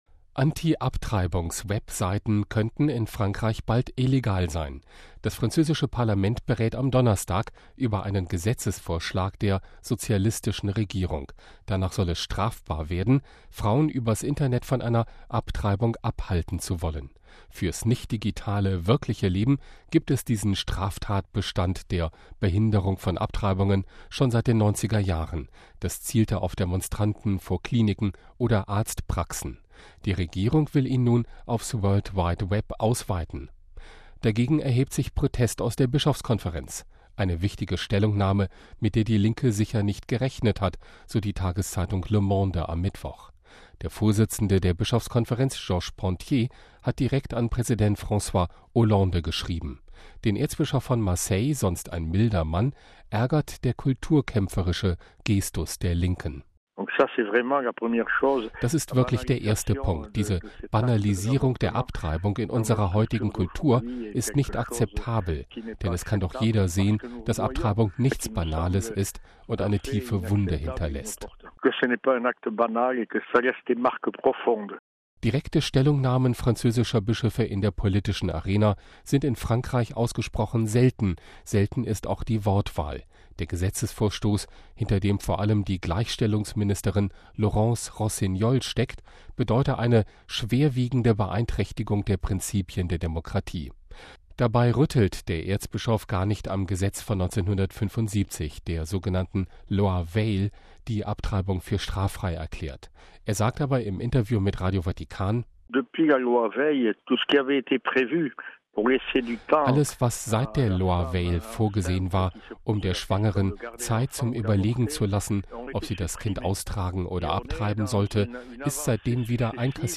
Er sagt im Interview mit Radio Vatikan: